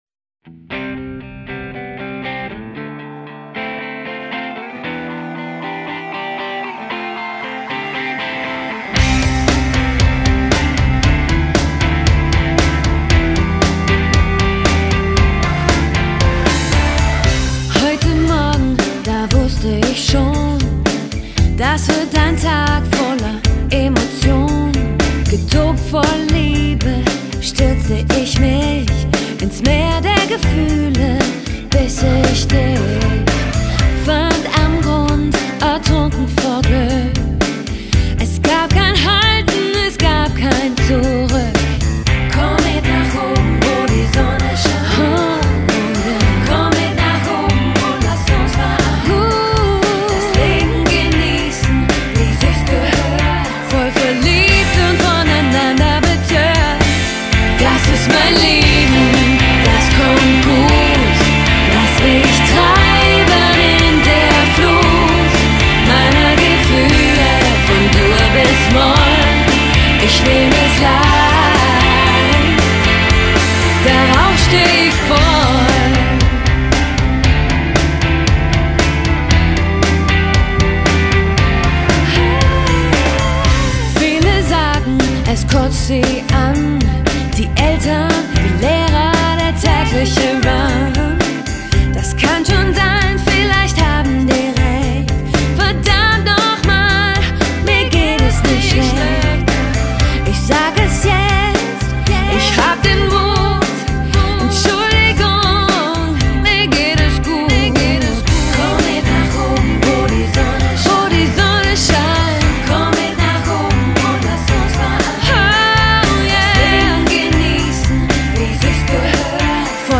Genre: Kampagnen-Musik